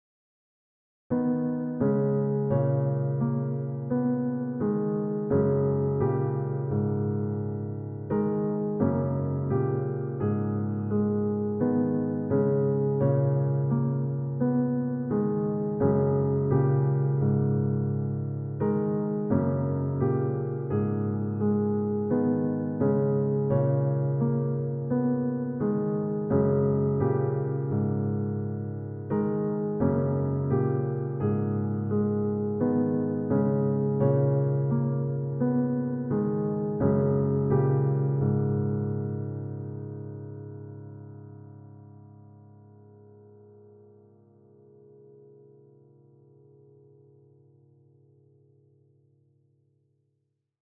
EN: The harmonic and rhythmic structure of the resulting music also changes in small steps.
In particular, the velocity of the piano and the sustain pedal are not yet influenced by the progressive harmonic structure.